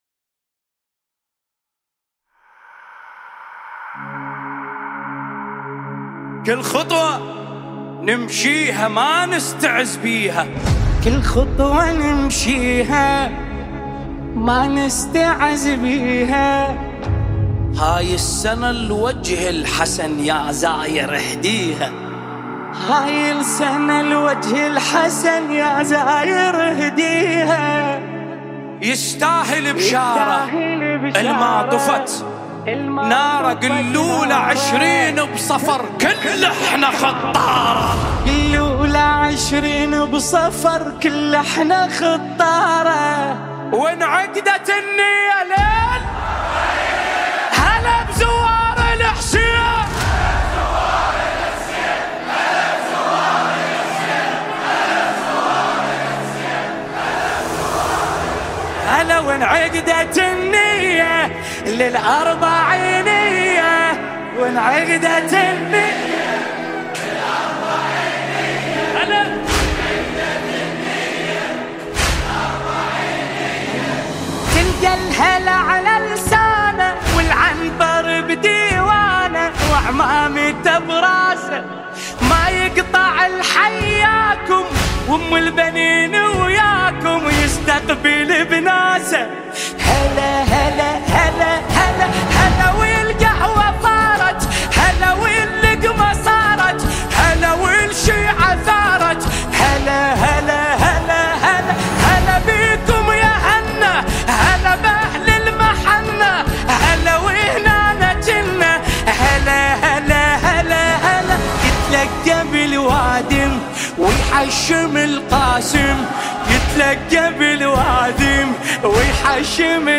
دانلود نوحه عربی